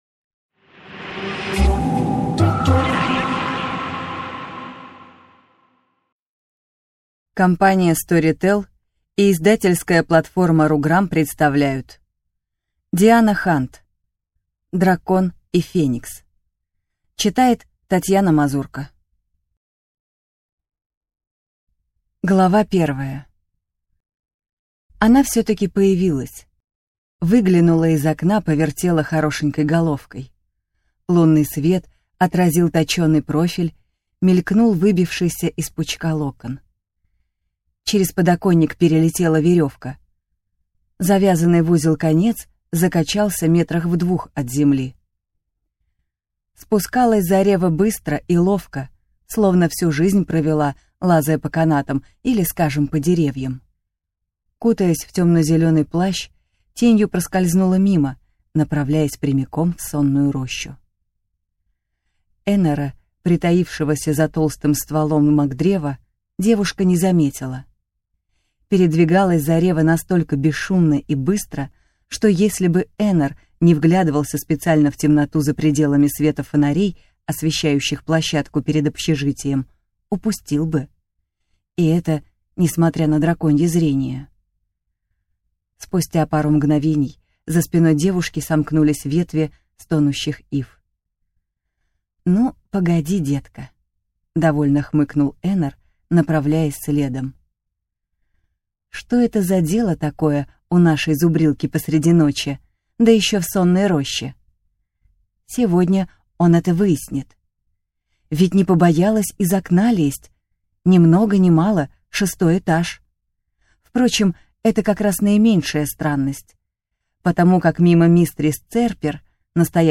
Аудиокнига Дракон и Феникс | Библиотека аудиокниг